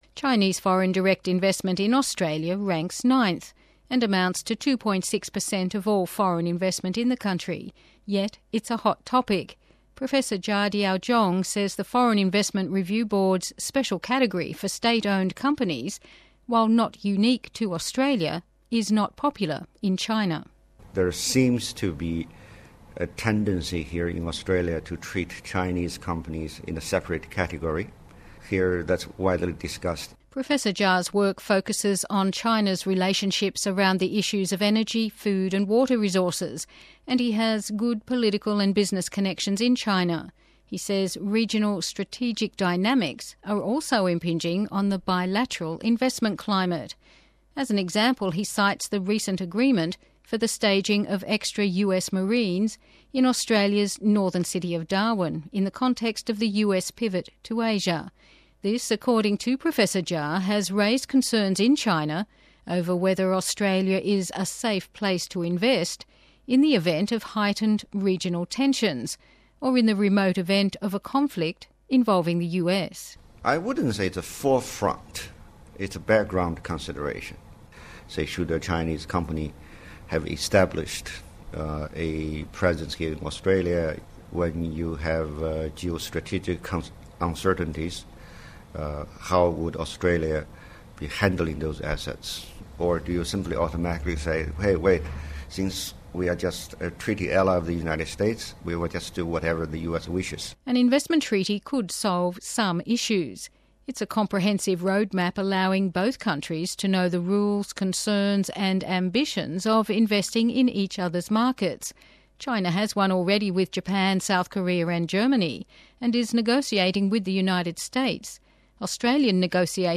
Correspondent
Speaker